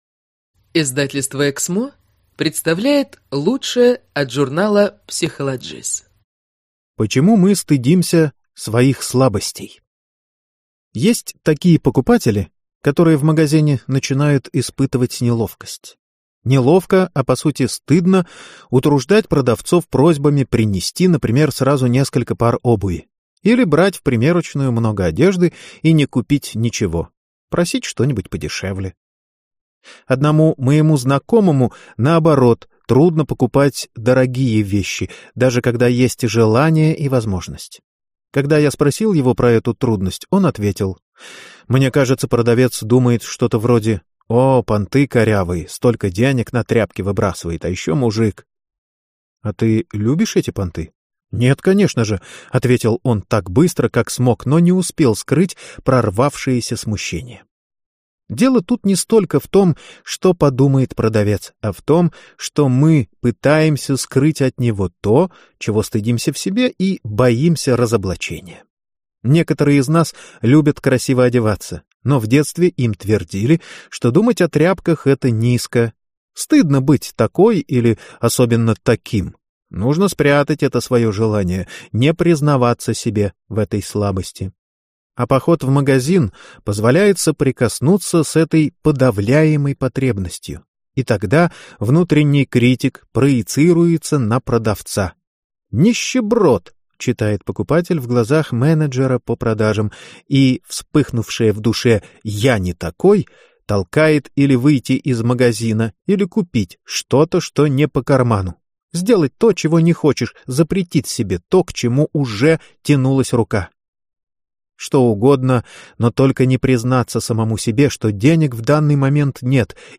Аудиокнига Меня никто не любит, даже я сама. Как найти опору в себе | Библиотека аудиокниг